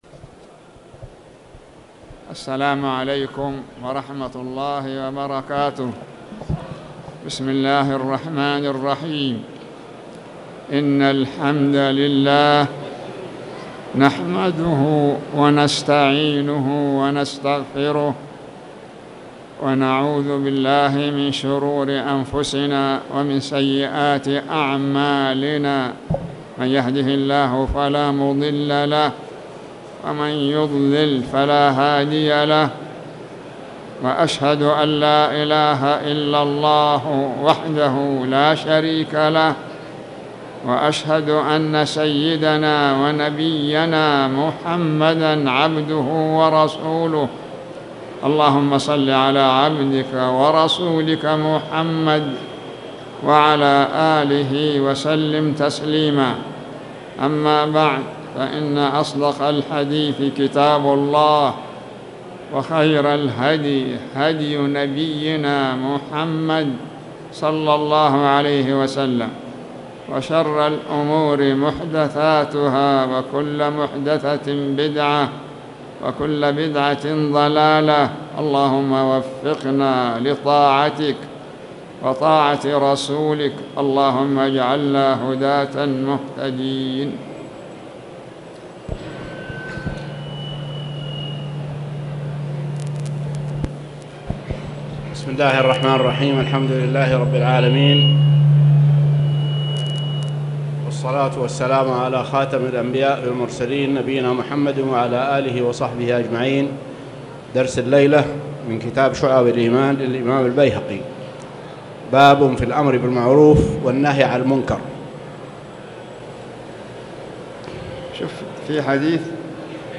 تاريخ النشر ٨ جمادى الآخرة ١٤٣٨ هـ المكان: المسجد الحرام الشيخ